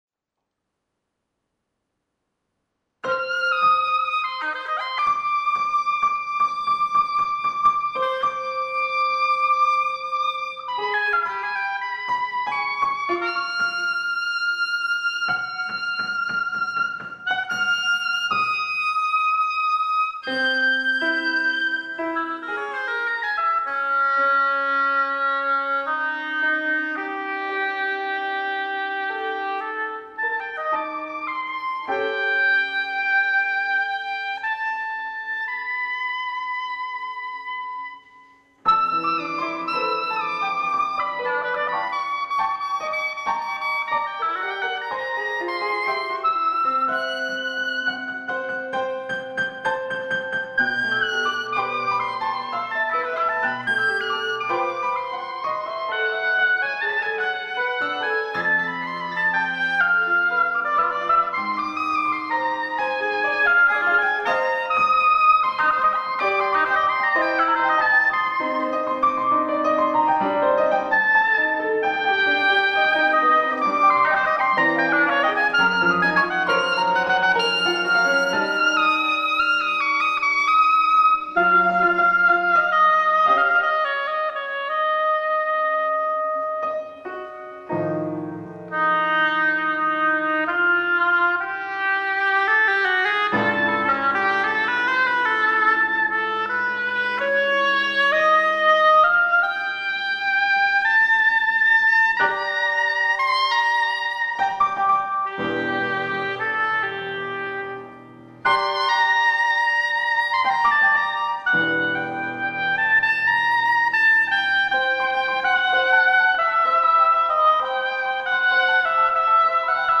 Sadly this recording is only modest in audio quality.
oboe
piano